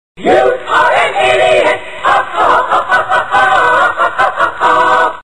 You are an idiot! - Meme Effect Sound